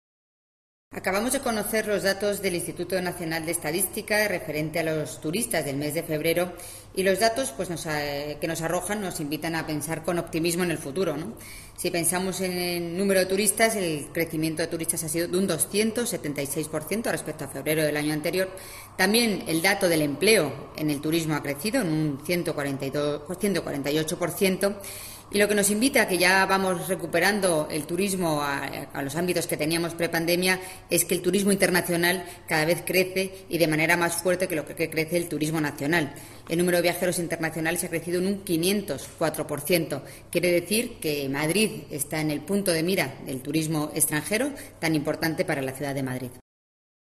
Nueva ventana:Almudena Maíllo, concejala delegada de Turismo